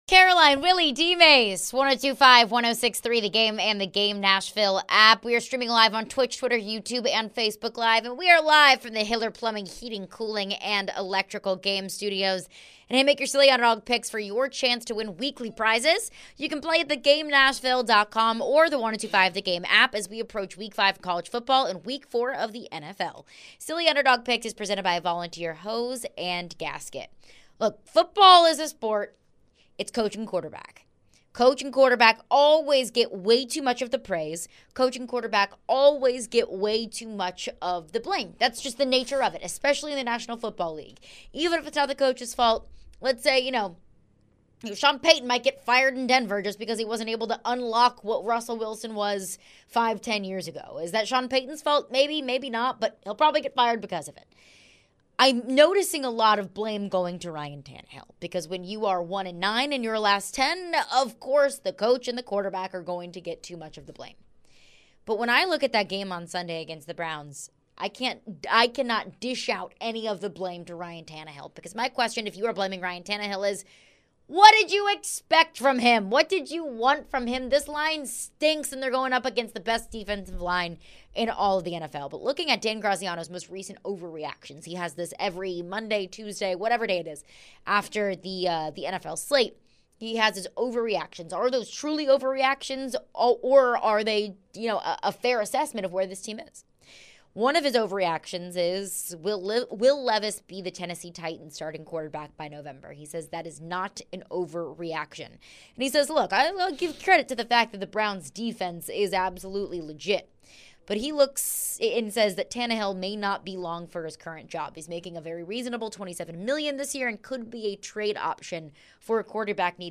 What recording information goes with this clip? answered a few phone calls on the Titans and more.